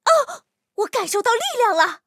犀牛补给语音.OGG